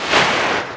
watr_in.ogg